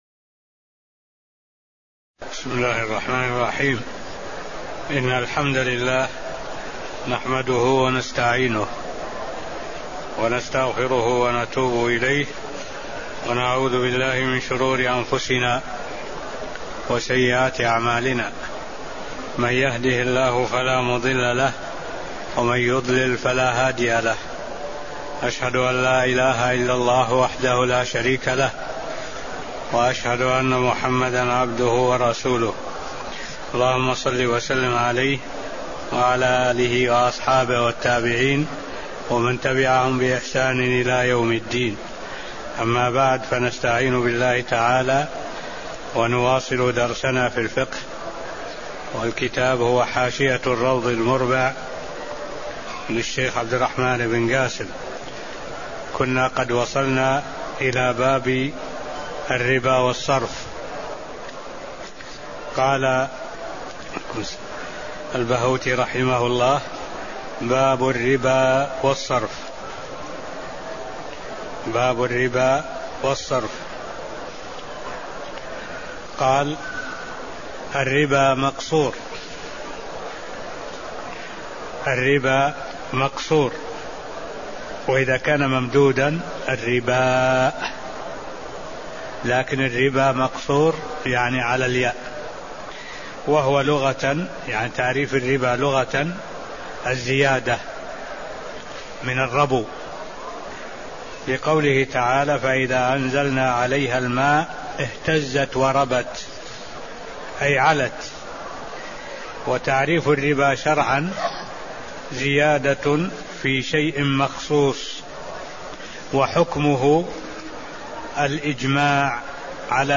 المكان: المسجد النبوي الشيخ: معالي الشيخ الدكتور صالح بن عبد الله العبود معالي الشيخ الدكتور صالح بن عبد الله العبود مقدمة في باب الربا و الصرف (01)